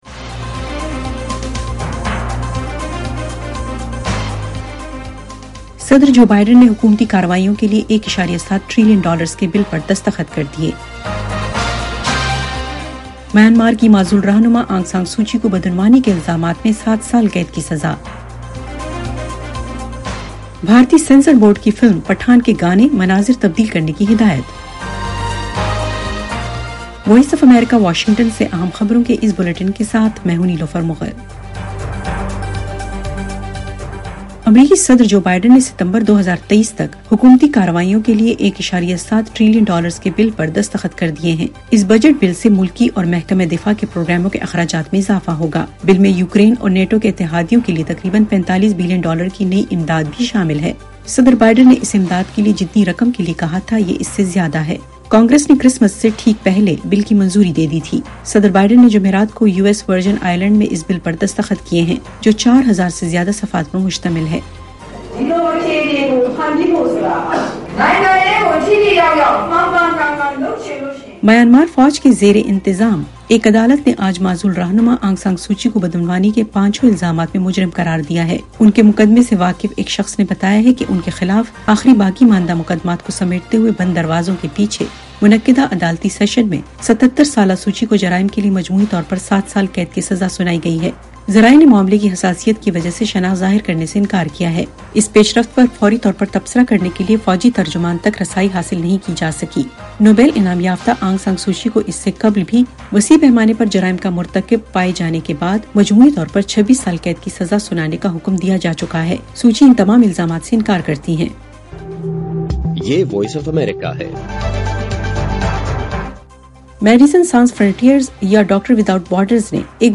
ایف ایم ریڈیو نیوز بلیٹن : شام 6 بجے